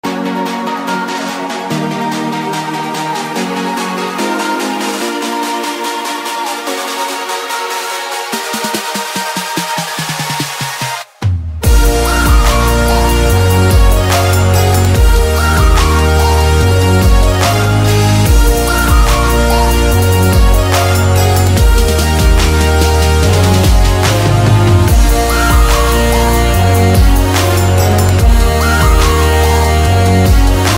outro song - MP3 Download
outro-song-made-with-Voicemod.mp3